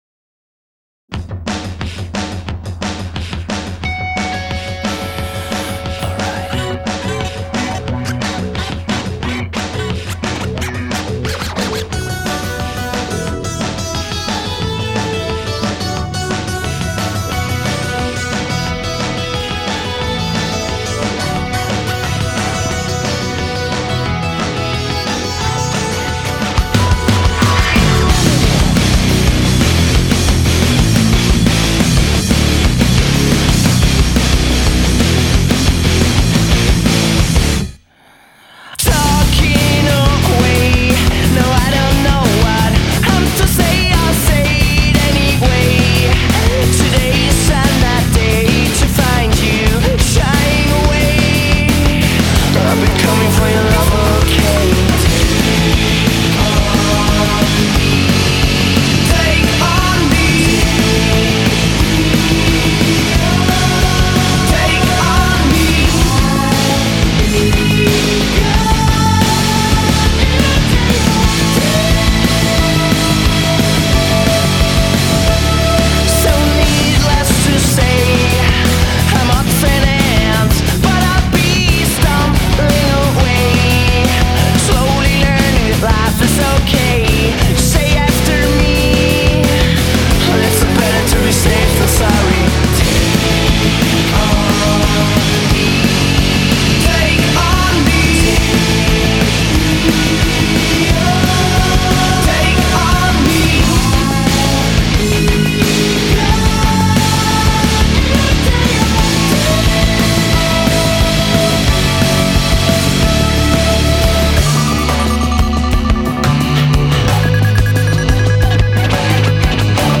Вокал похож на Muse (но скорее всего не они)...